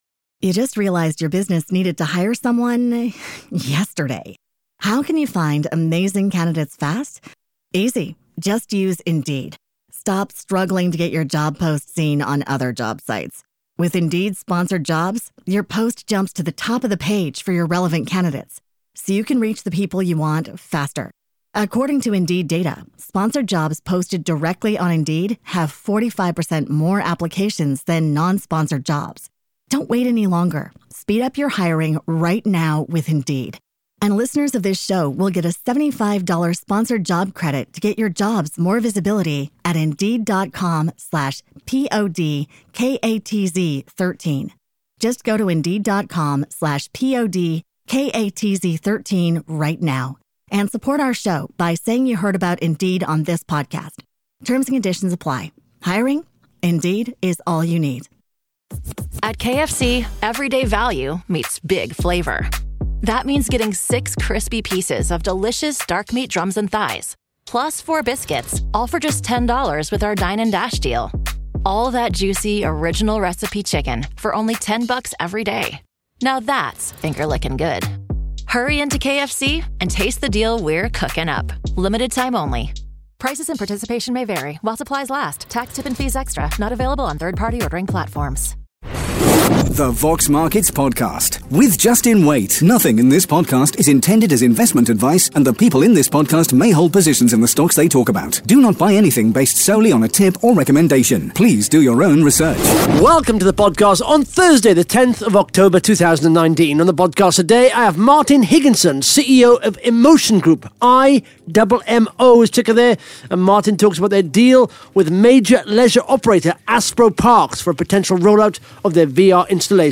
(Interview starts at 12 minutes 40 seconds)